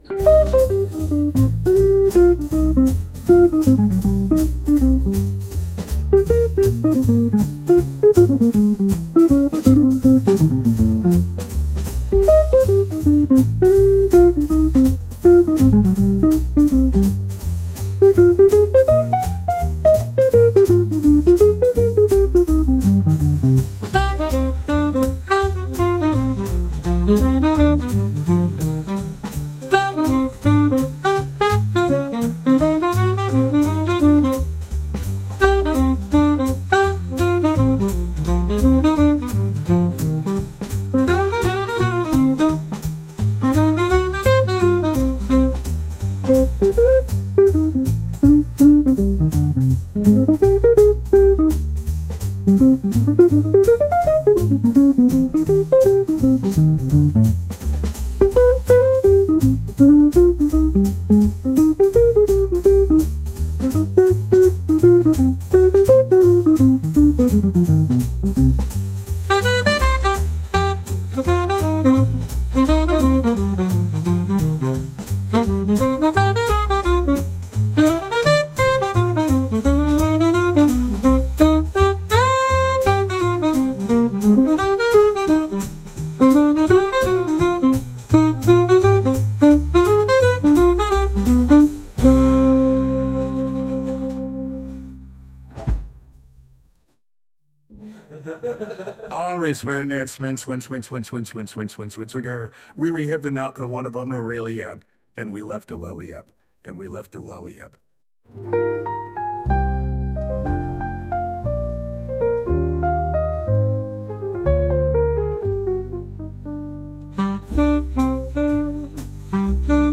サックスとピアノのジャズ音楽です。